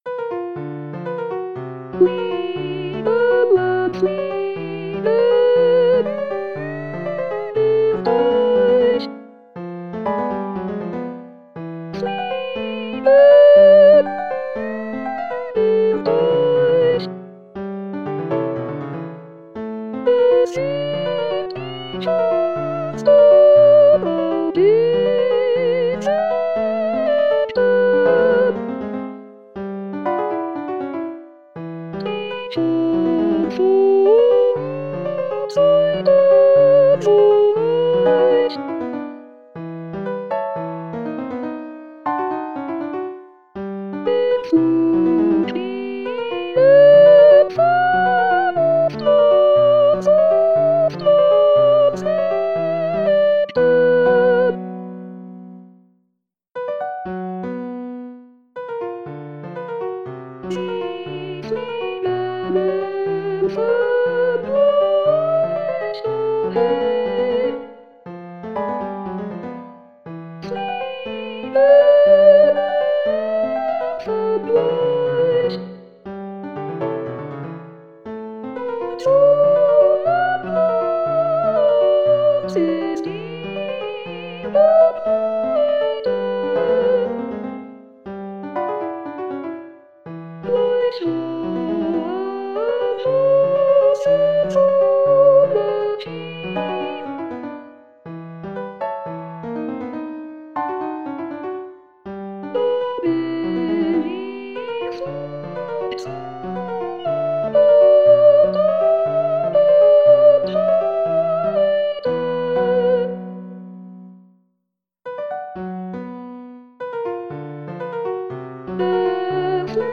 mp3-Aufnahme: Kunstlied Sopran